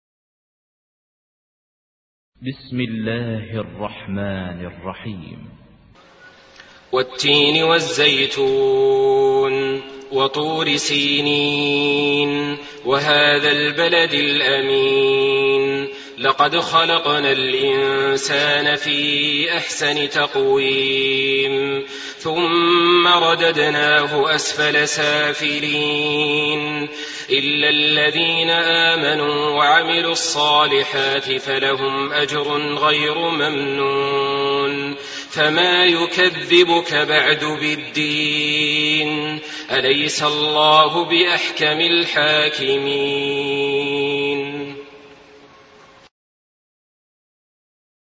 Surah At-Tin MP3 by Saleh Al-Talib in Hafs An Asim narration.
Murattal Hafs An Asim